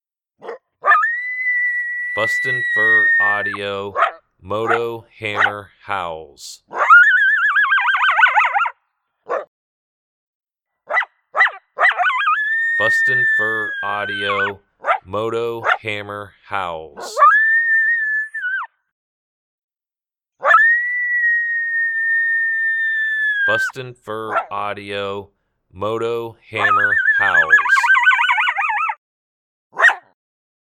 Juvenile male Coyote howling with aggression.
BFA Moto Hammer Howls Sample.mp3